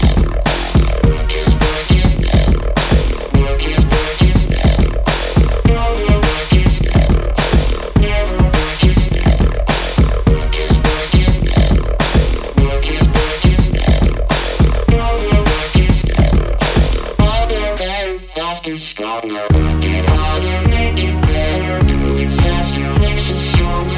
no Backing Vocals R'n'B / Hip Hop 5:14 Buy £1.50